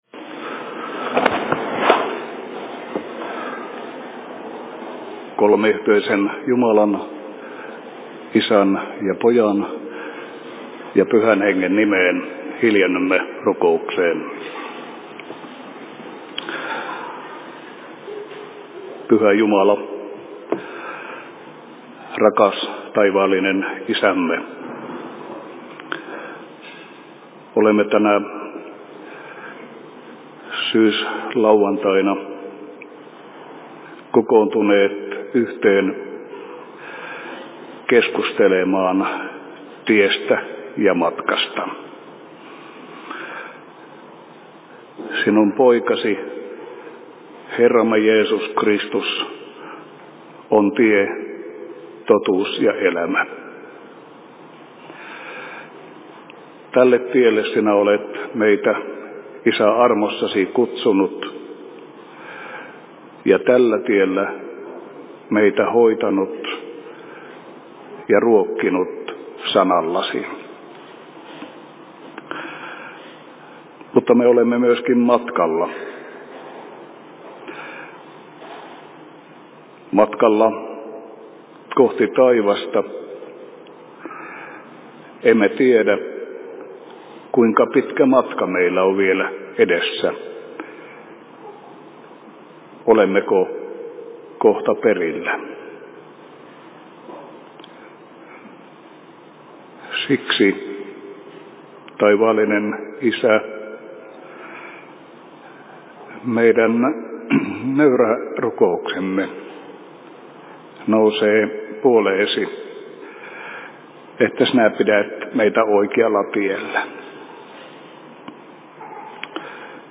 Nuortenilta/Alustus Oulun RY:llä 30.10.2021 17.55
Paikka: Rauhanyhdistys Oulu